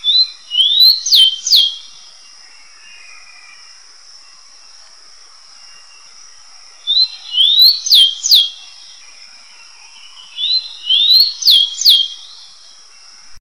Zonotrichia capensis - Chingolo común
chingolo.wav